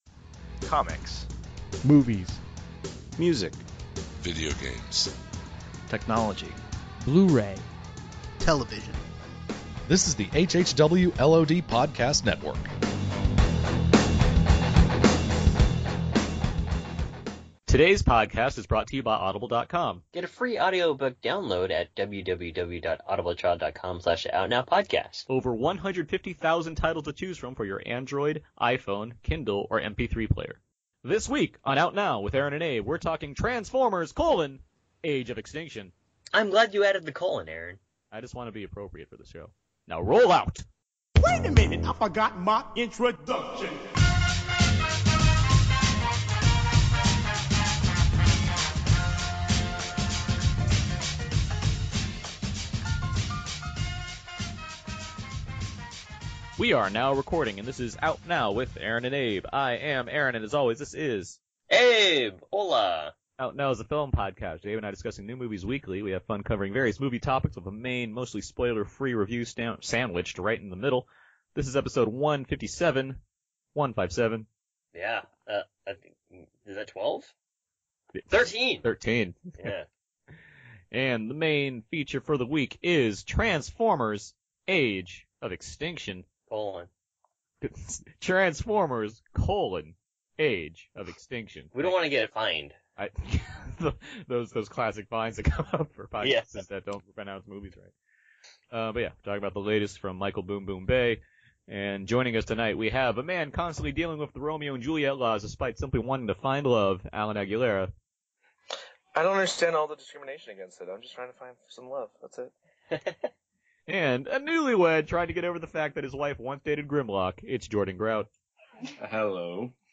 The guys battled some challenging internet connections to get this episode recorded, so boot it up and roll out!